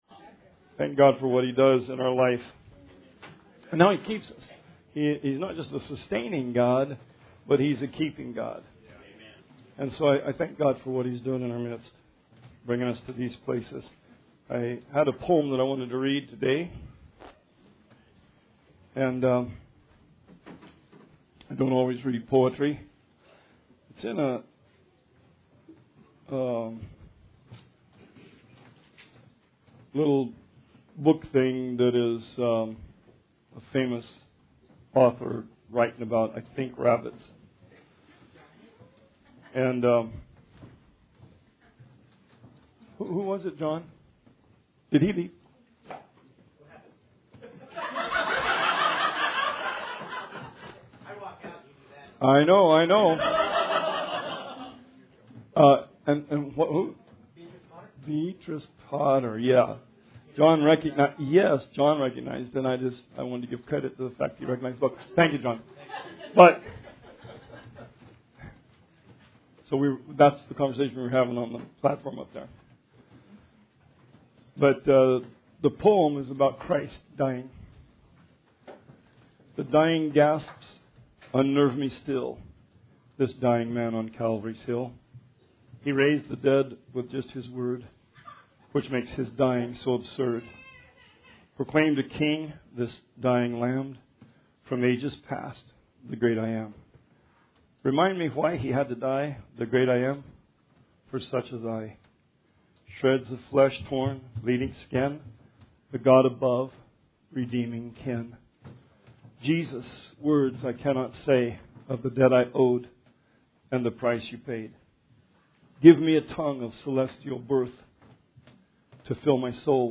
Sermon 9/29/19 – RR Archives